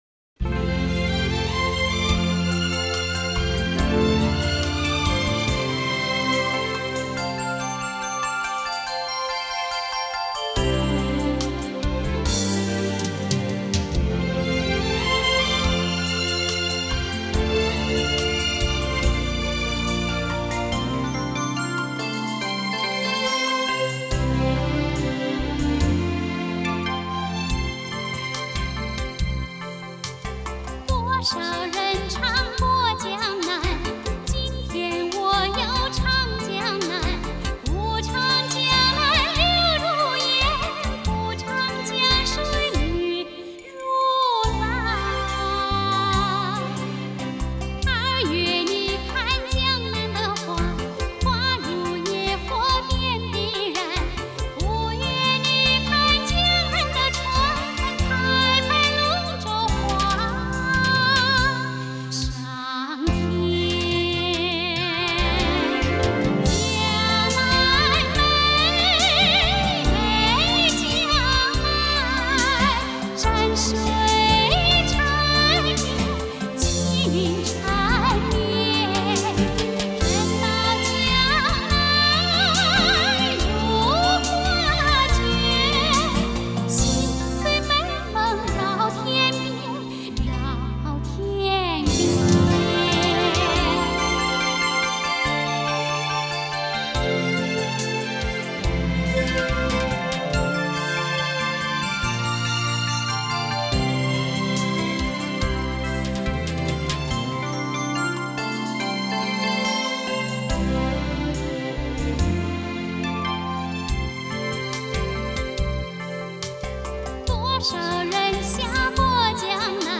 Жанр: Chinese pop / Chinese folk